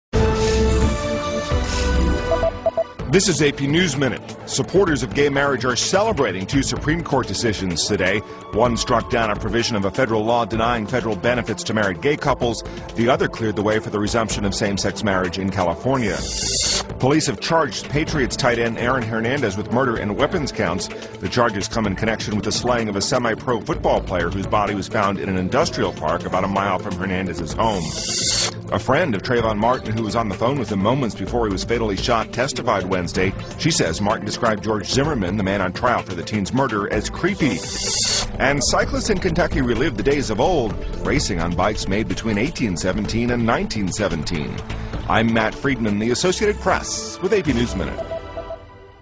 在线英语听力室美联社新闻一分钟 AP 2013-07-01的听力文件下载,美联社新闻一分钟2013,英语听力,英语新闻,英语MP3 由美联社编辑的一分钟国际电视新闻，报道每天发生的重大国际事件。电视新闻片长一分钟，一般包括五个小段，简明扼要，语言规范，便于大家快速了解世界大事。